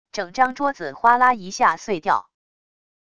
整张桌子哗啦一下碎掉wav音频